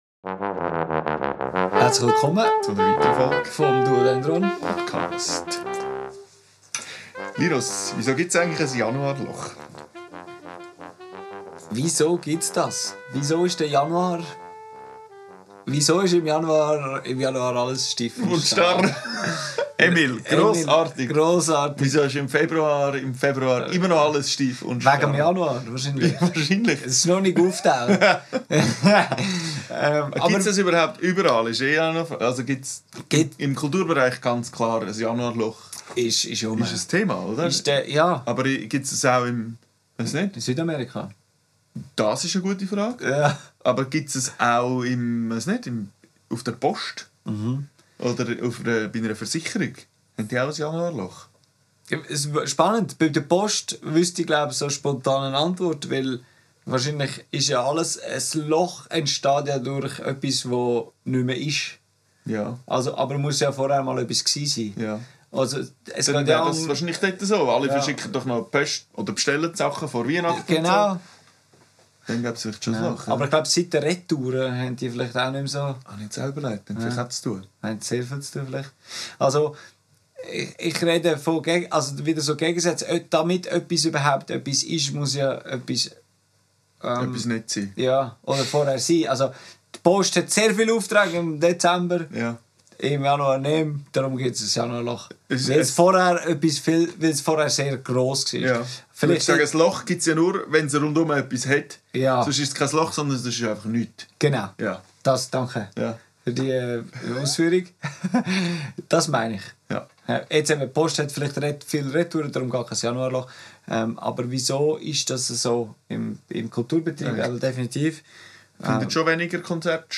Aufgenommen am 18.12.2024 im Atelier